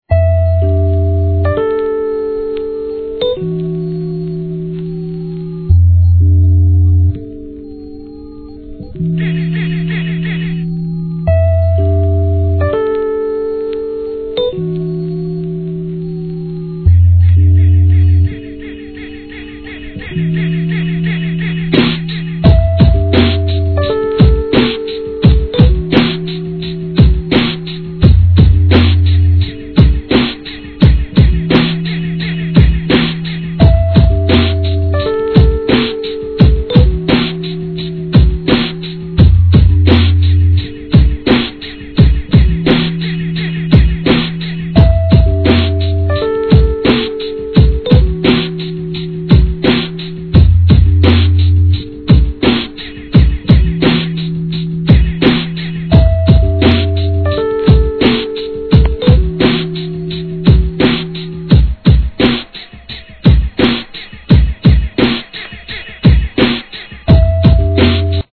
DOPEブレイクビーツ・アルバム!!